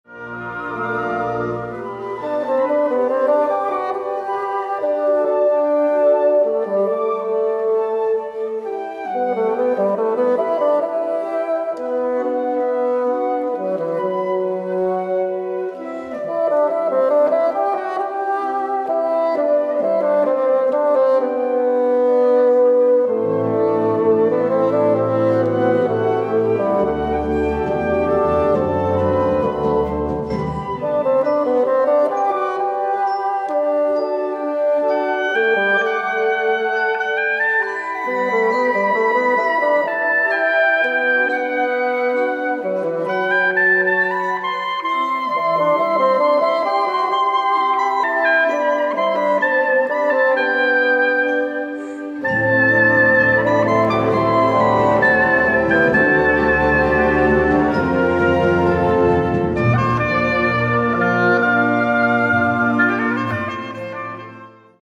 Besetzung: Blasorchester
Solo für Oboe und Fagott.